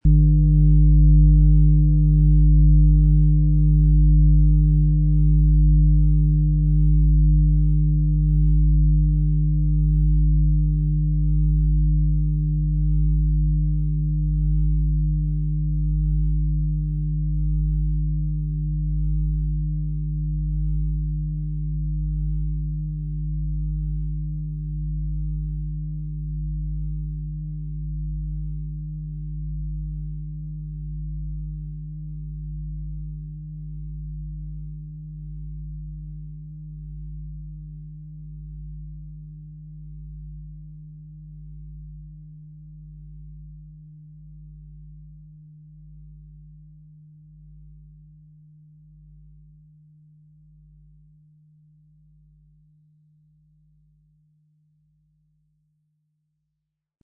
XXXL Fußreflexzonenschale
• Sanfte, tiefgehende Schwingungen: Berühren Körper und Herz.
MaterialBronze